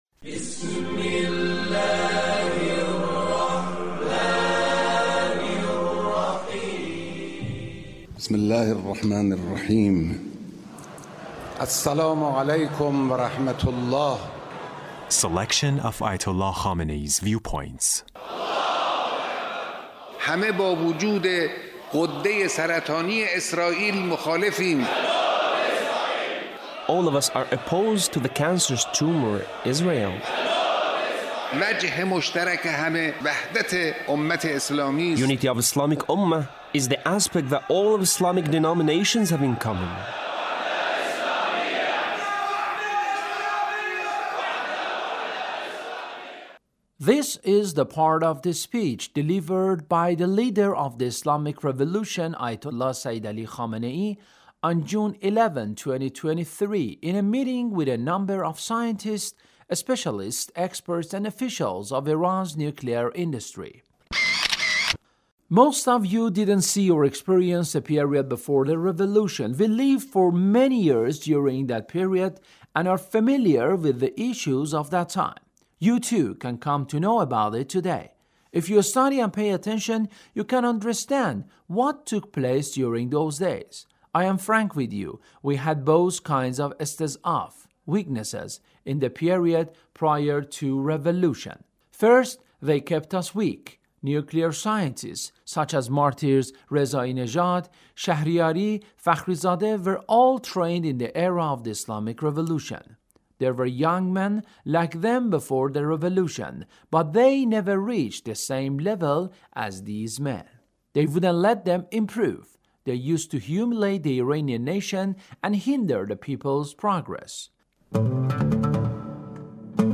Leader's Speech (1789)
Leader's Speech in a meeting with a number of scientists,and officials of Iran’s nuclear industry